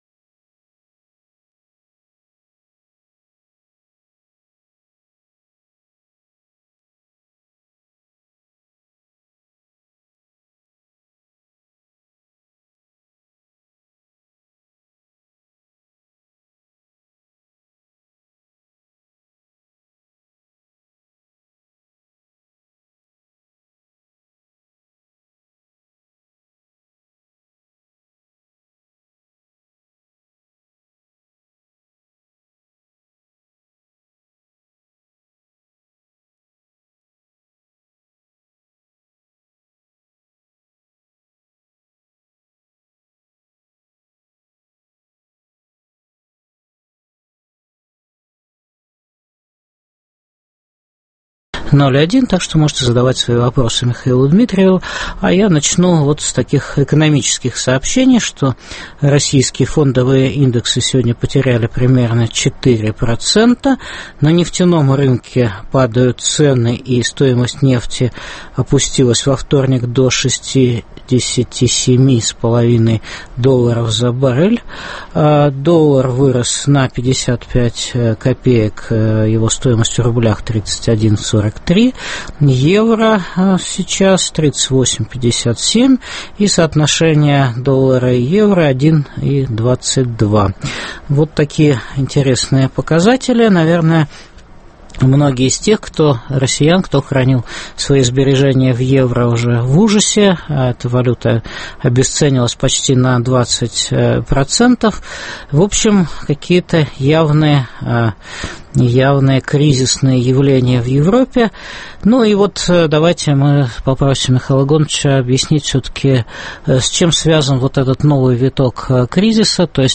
Что ждет экономику России на нынешнем этапе мирового кризиса? В студии - Президент Центра стратегических разработок, доктор экономических наук Михаил Дмитриев.